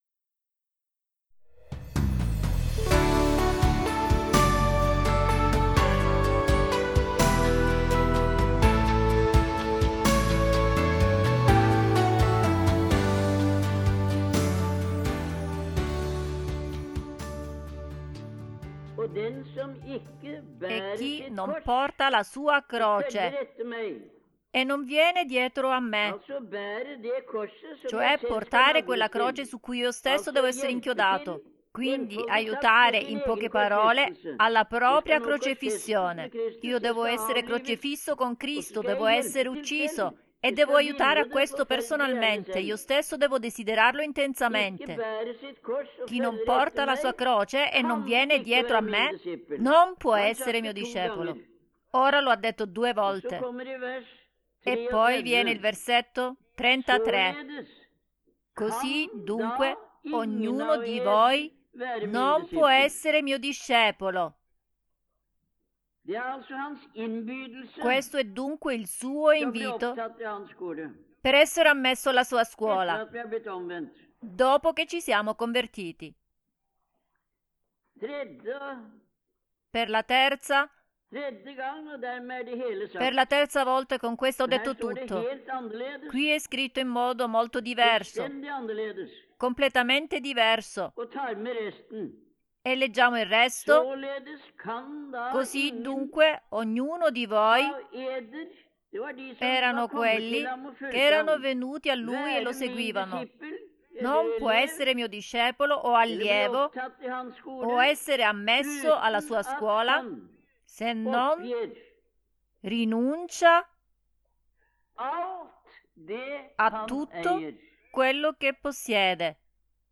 Estratto di un messaggio di gennaio 1969